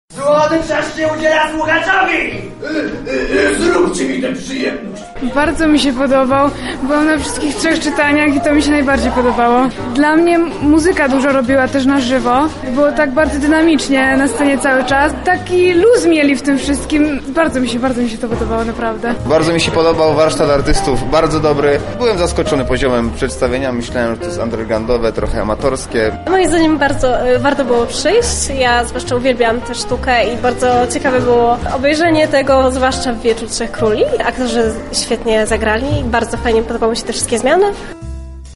Całe wydarzenie miało miejsce wczoraj (6.01) w piwnicach Centrum Kultury.